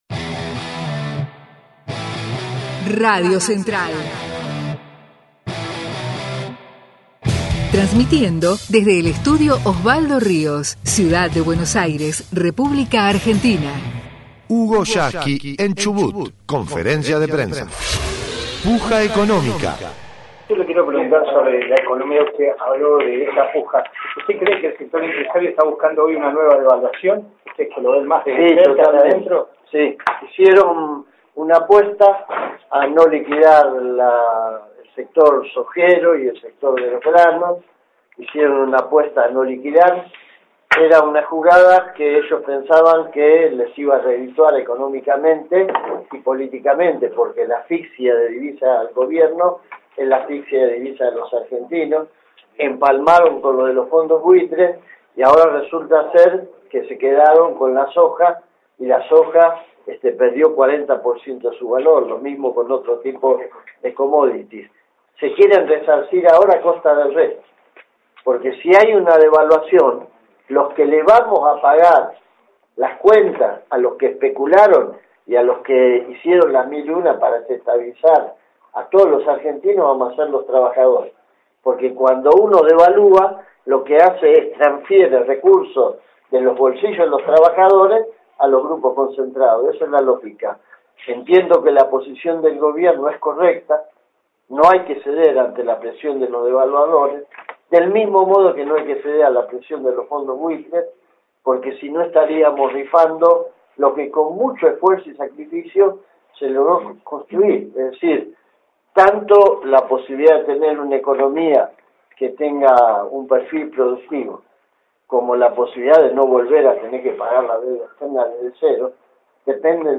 HUGO YASKY en CHUBUT - conferencia de prensa - 8ª parte: LA PUJA ECONOMICA